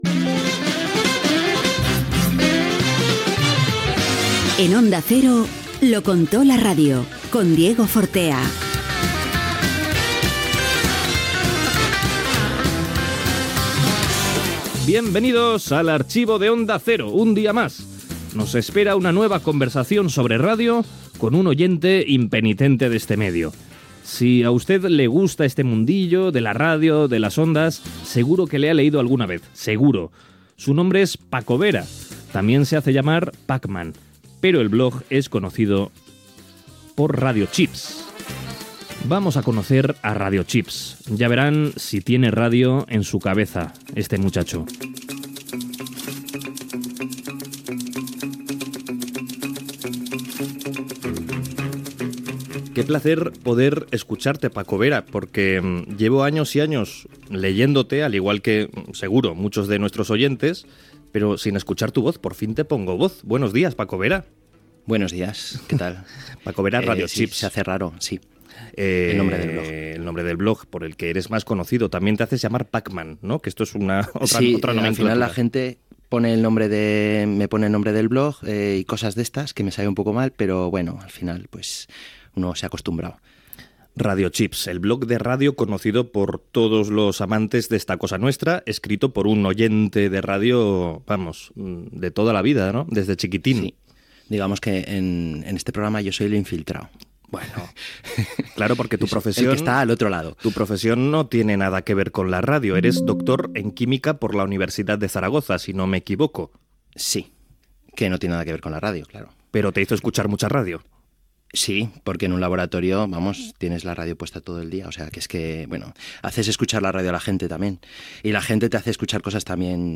Careta del programa, presentació i fragment d'una entrevista
FM